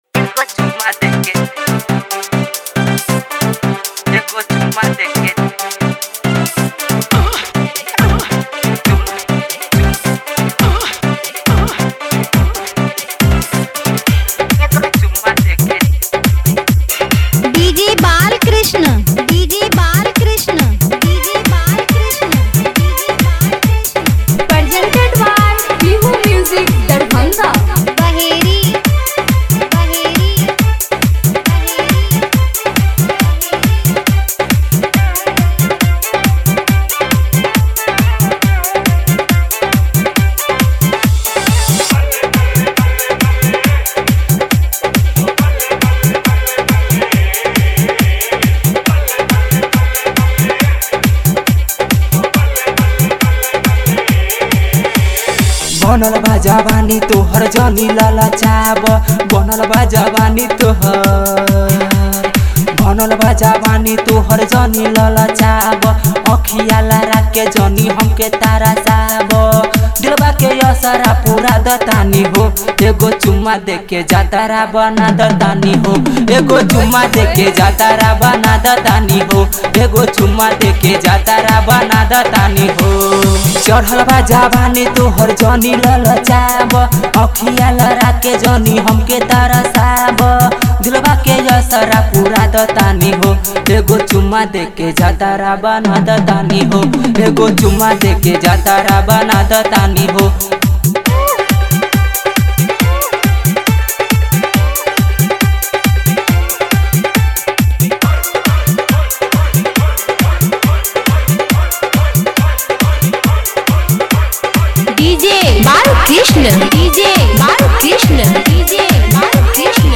New Bhojpuri Song